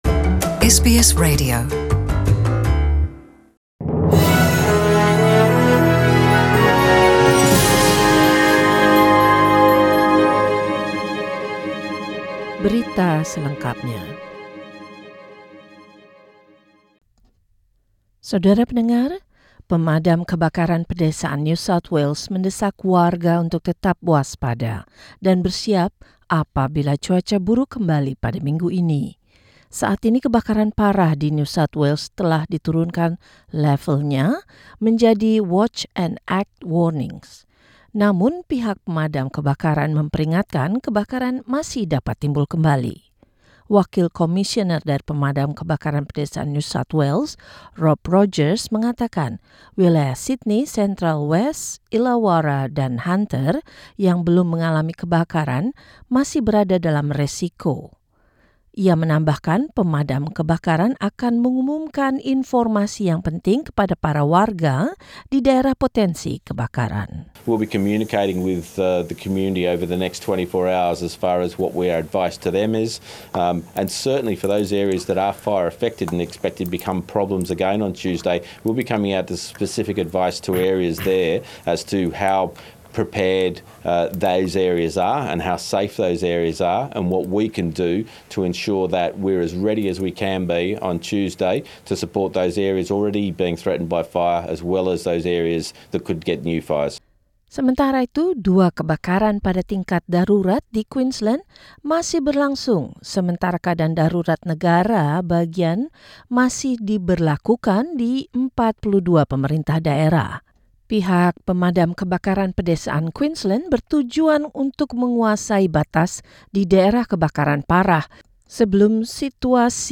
SBS Radio News in Indonesian